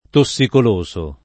tossicoloso [ to SS ikol 1S o ] agg.